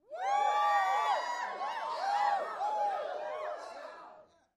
( 1-3 ) Crowd ( 20 ): Rowdy, Short Cheers, Mostly Male. Cheers, Male.